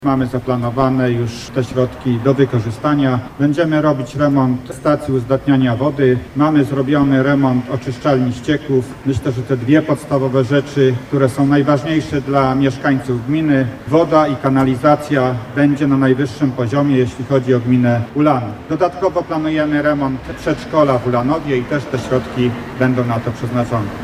Poinformował o tym burmistrz Ulanowa Stanisław Garbacz: